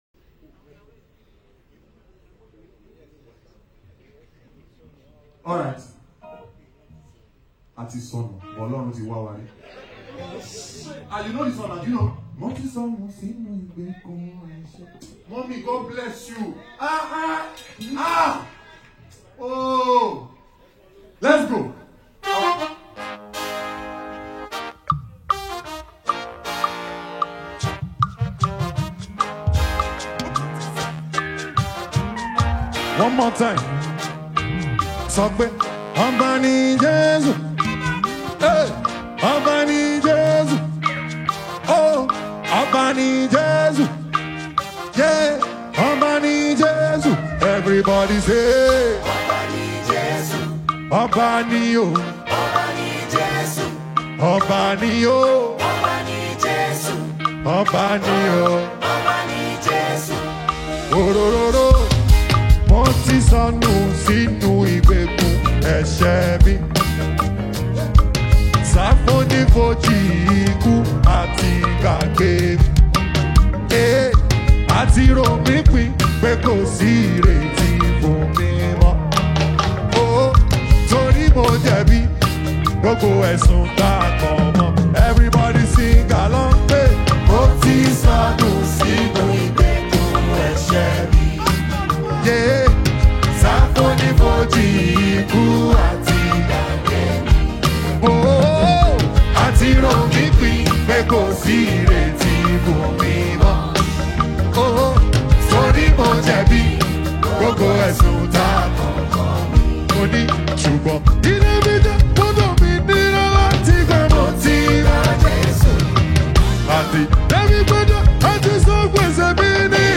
Christian/Gospel